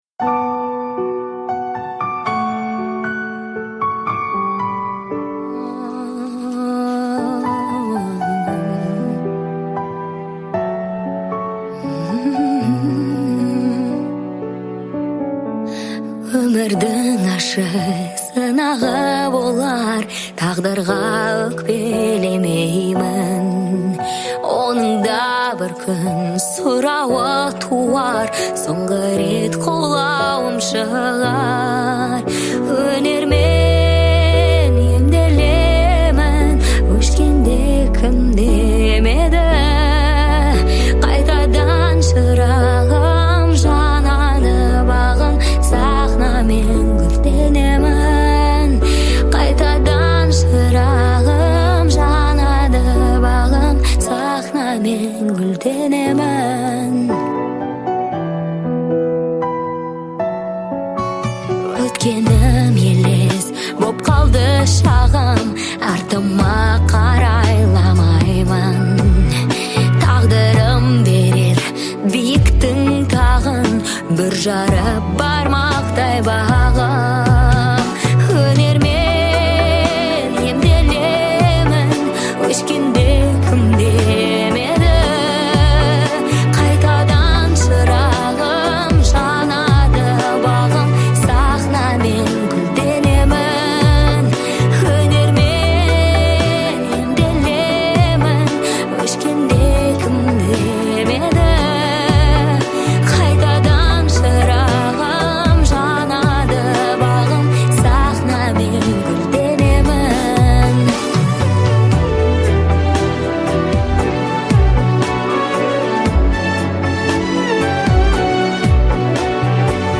это проникновенная песня в жанре казахского поп-фолка